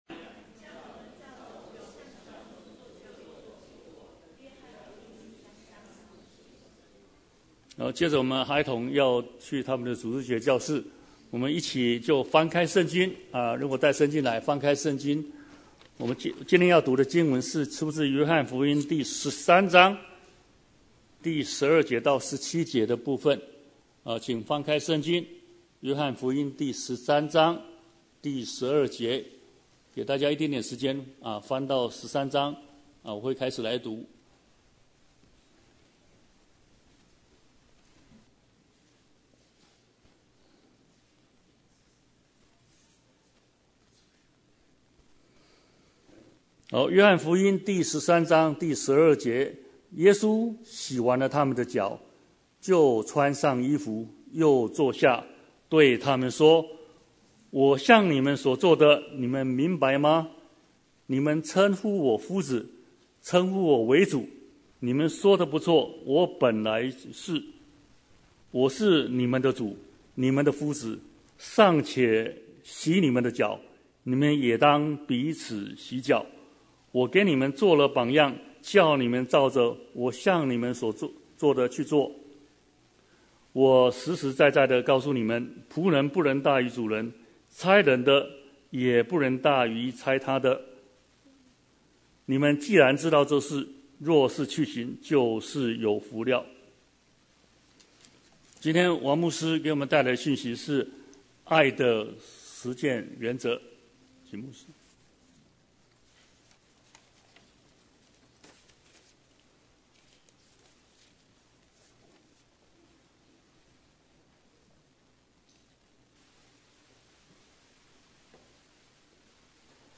Bible Text: 約翰13:12-17 | Preacher